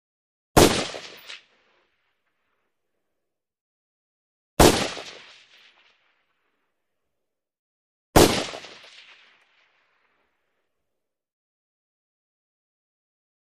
Mini-14 Automatic: Single Shot ( 3x ); Mini-14 Automatic Fires Three Individual Shots, Long Echo Tail On Each Shot. Medium Perspective. Gunshots.